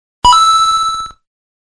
coin